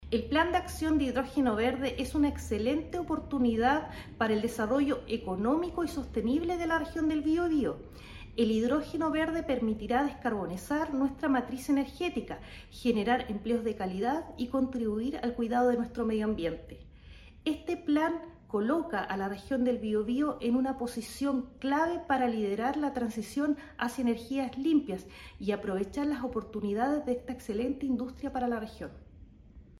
En el caso específico de la región del Biobío, la Seremi de Energía, Daniela Espinoza, resaltó su relevancia.